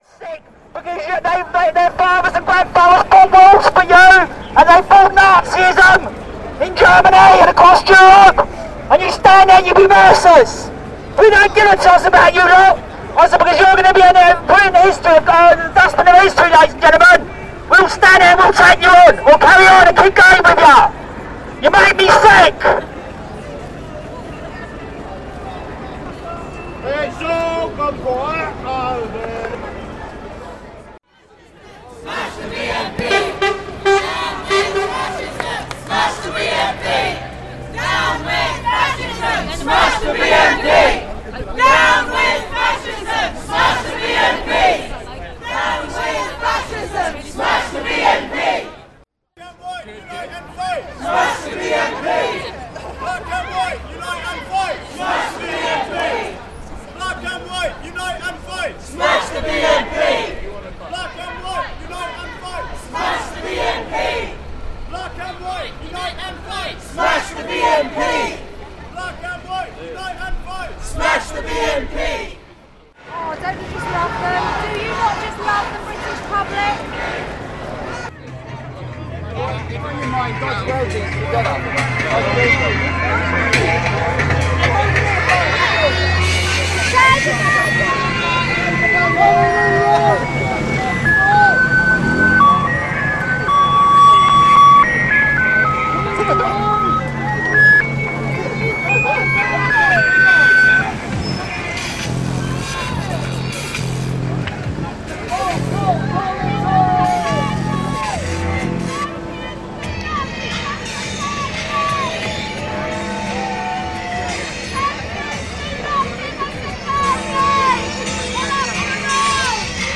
Montage of sounds from the Demonstrations on Saturday. Fascists V Anti-Fascists Oh and the Badgers marched.